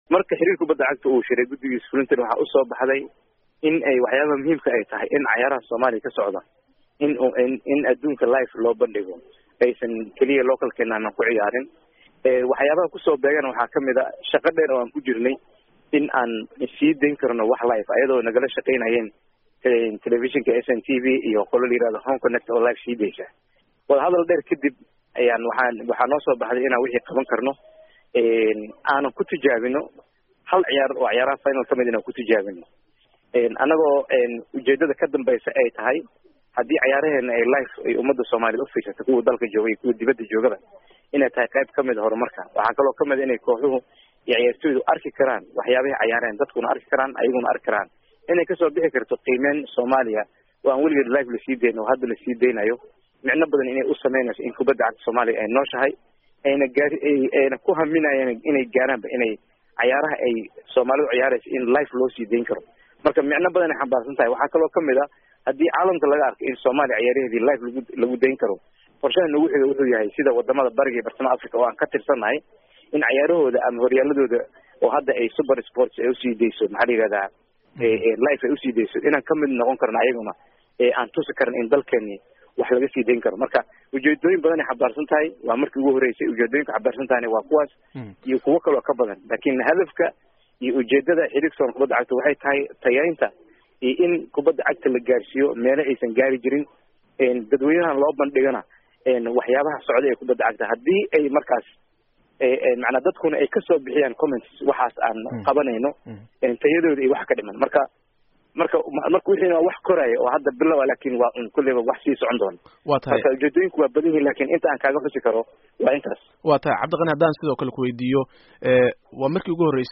Wareysi